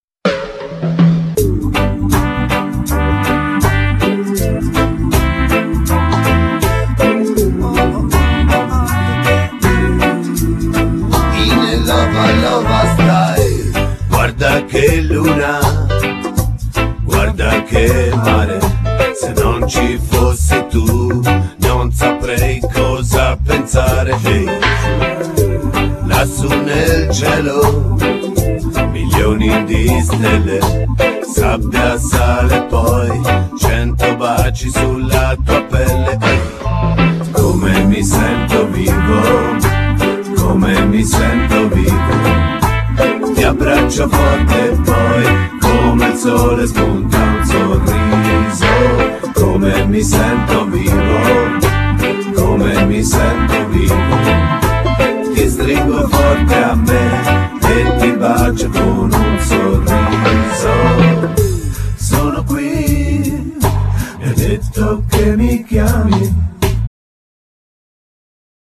Genere : Raggae
è un brano in puro "Iovers style" giamaicano